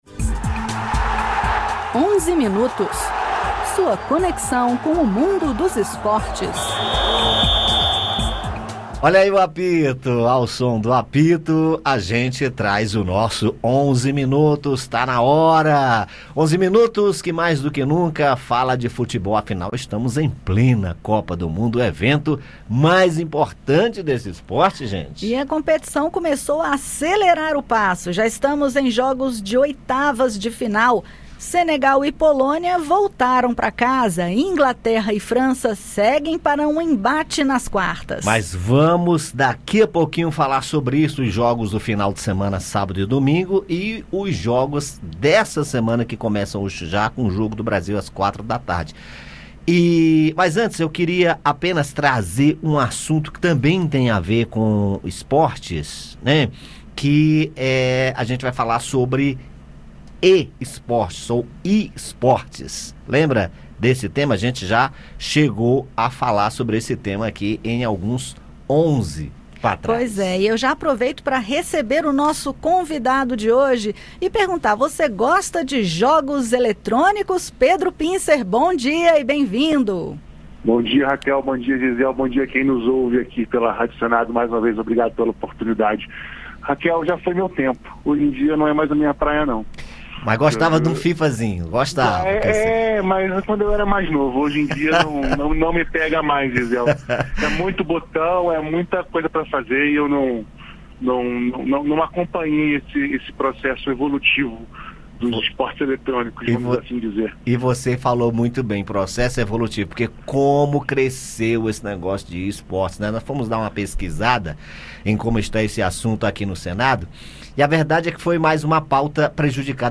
Acompanhe os comentários e a agenda dos jogos desta segunda-feira (5): Croácia x Japão e Brasil x Coreia do Sul.